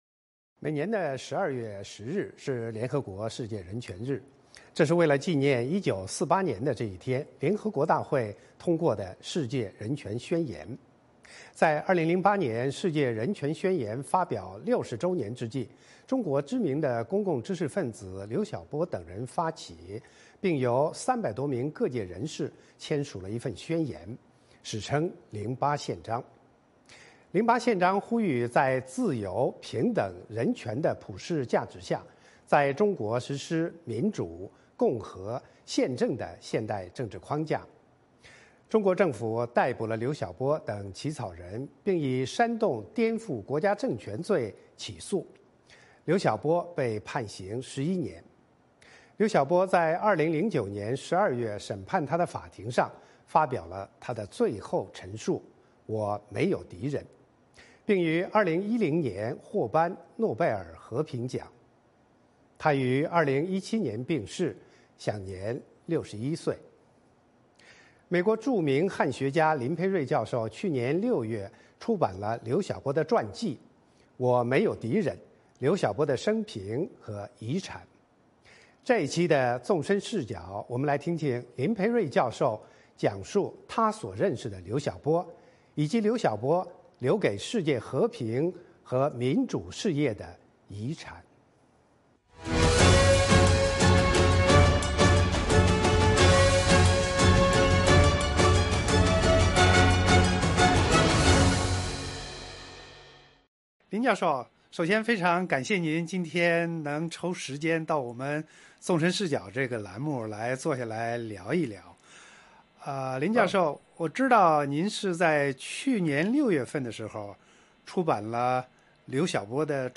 专访林培瑞：我所认识的刘晓波